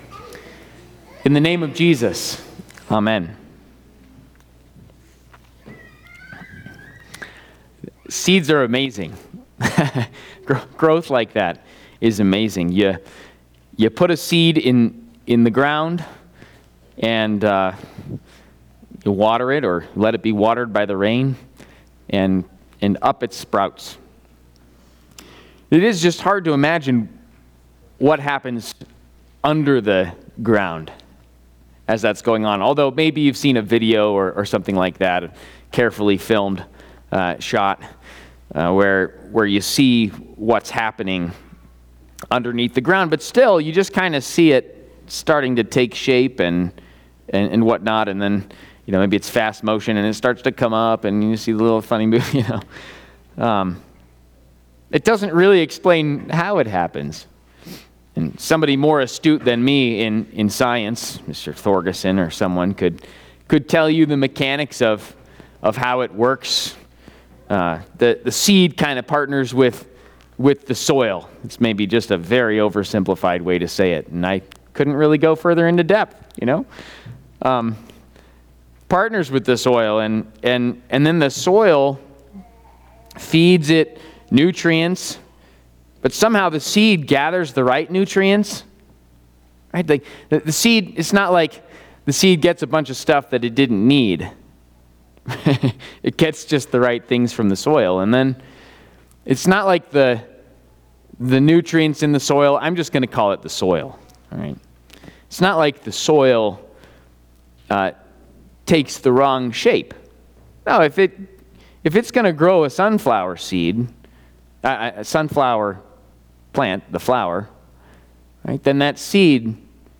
2025 17th Sunday After Pentecost